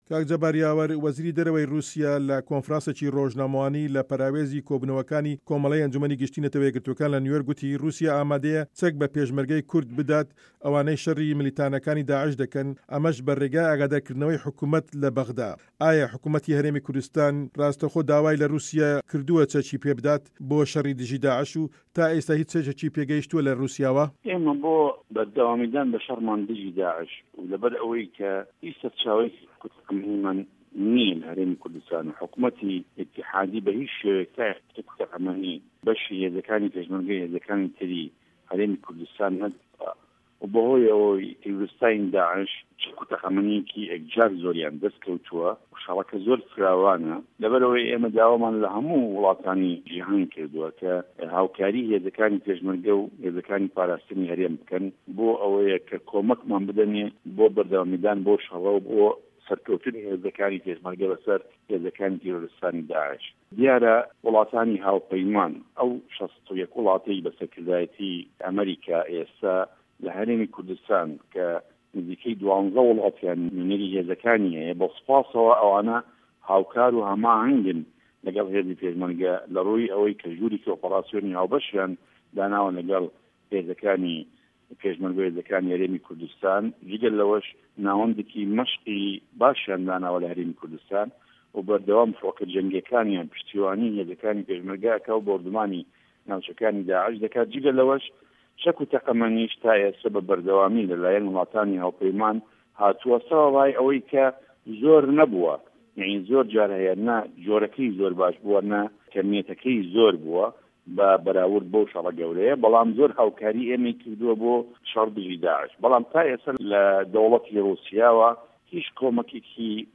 وتووێژ لەگەڵ جەبار یاوەر